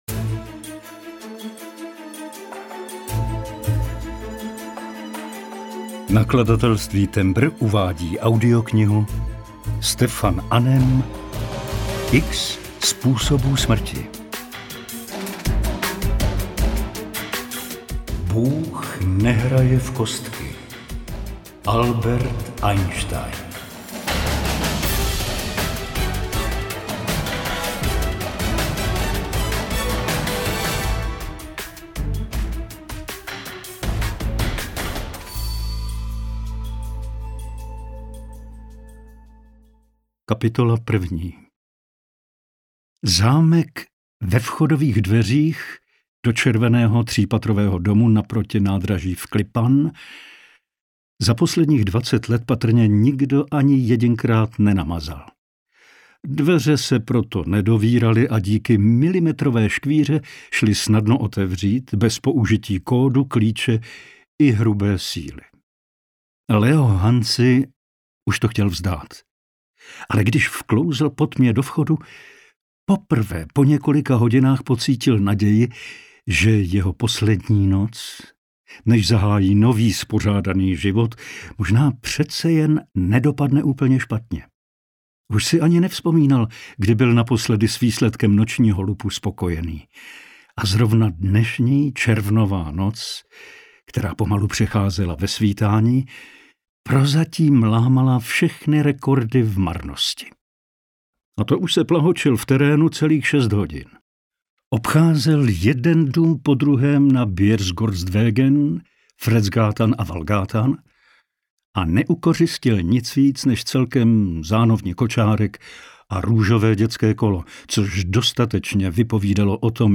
X způsobů smrti – 2. vydání audiokniha
Ukázka z knihy